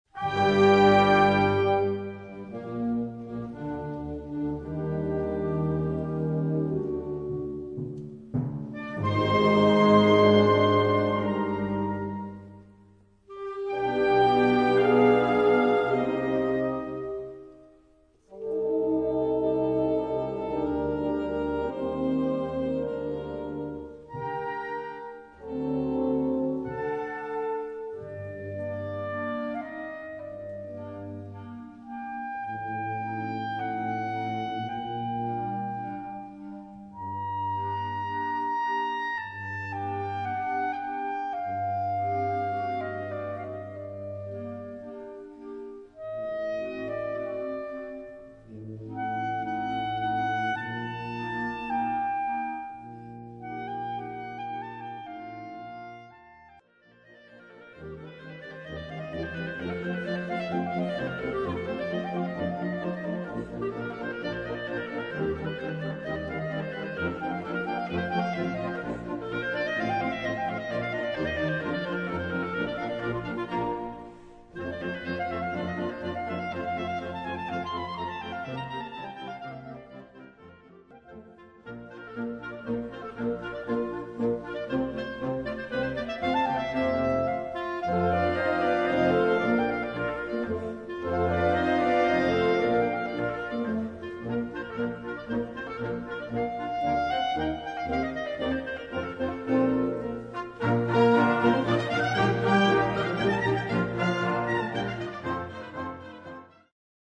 Gattung: Solo für Klarinette
Besetzung: Blasorchester
Soloklarinette und Sinfonisches Blasorchester